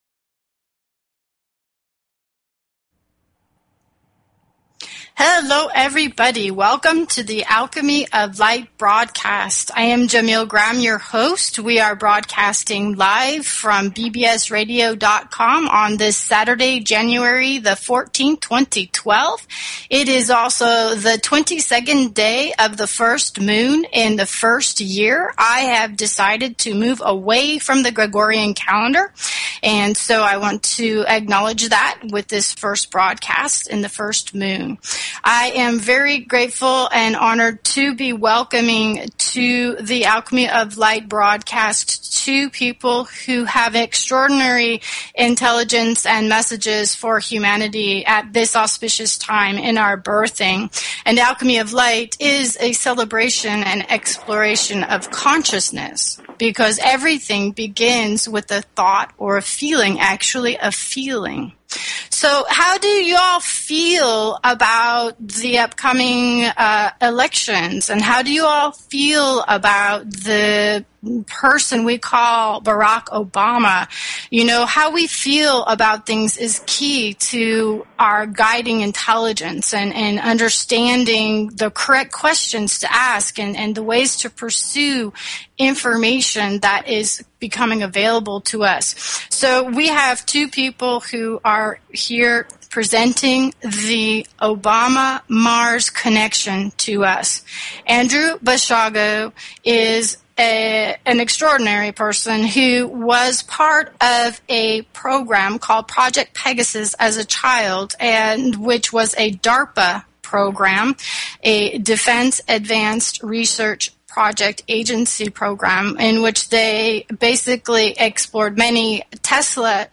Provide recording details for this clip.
This subject was barely scratched in this brief interview.